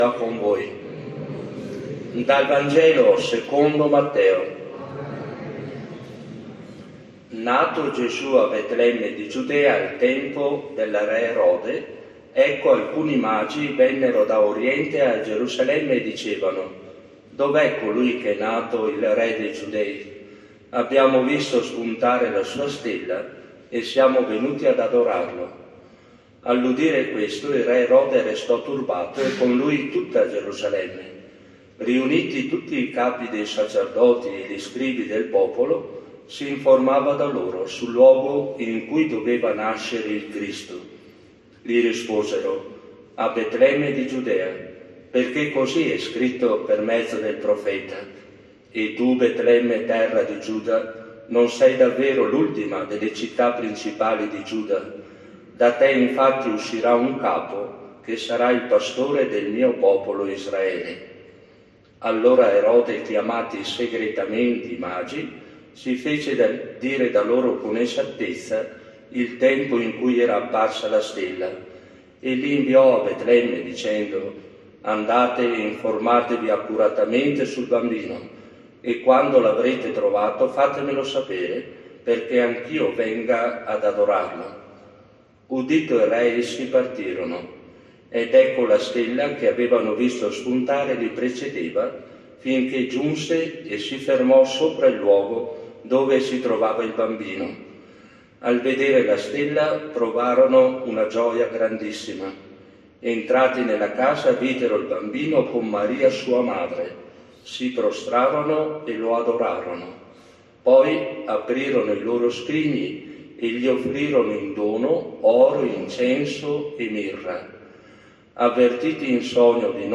Omelia-Epifania-2025.mp3